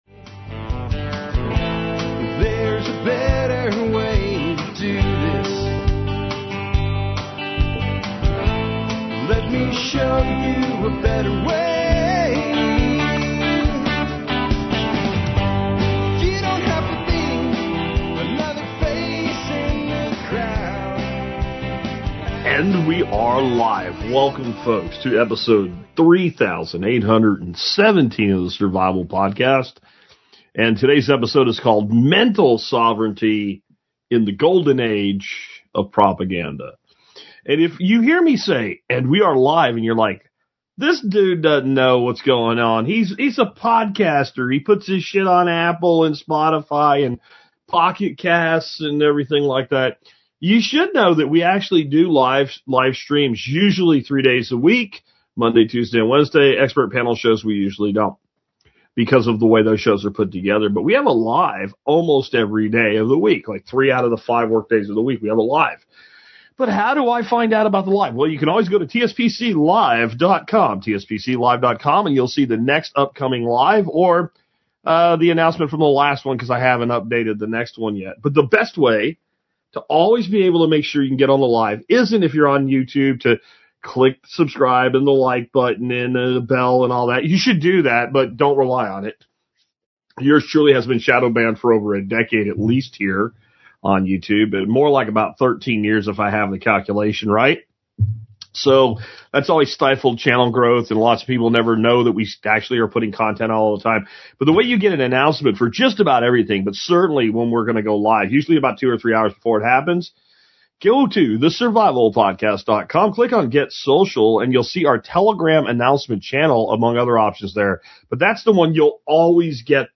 The survival podcast is the only online talk show 100% dedicated to modern survivalism, sustainability, alternative energy & thriving in changing economic times.